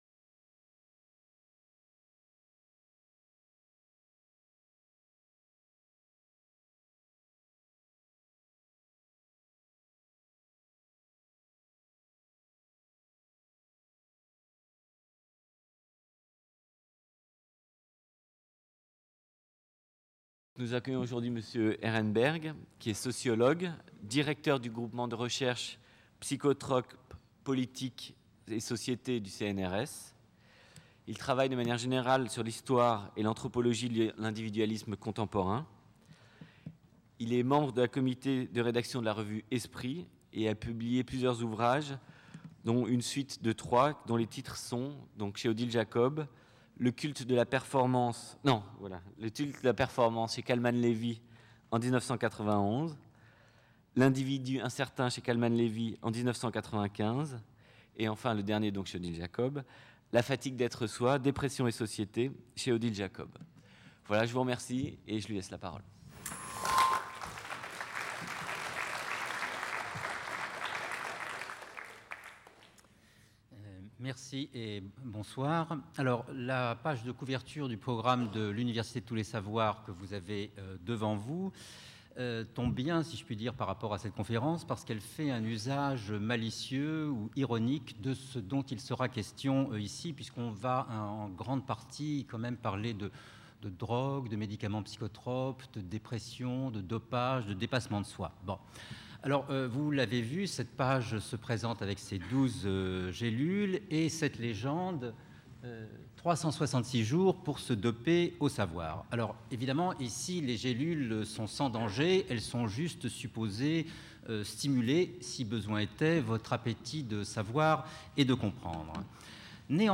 Le basculement d'une société organisée par la discipline à une société fonctionnant à l'autonomie est l'objet de cette conférence. Je suivrai ici le fil directeur des transformations de la drogue et des pathologies mentales, plus particulièrement celui de la dépression, dans nos sociétés en prenant comme angle d'attaque les changements ayant affecté l'individualité contemporaine au cours de la deuxième moitié du XXe siècle et les tensions qui le traversent.